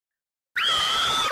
Sound Effects
Screaming Girl 1